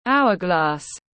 Dáng người đồng hồ cát tiếng anh gọi là hourglass, phiên âm tiếng anh đọc là /ˈaʊə.ɡlɑːs/ .
Hourglass /ˈaʊə.ɡlɑːs/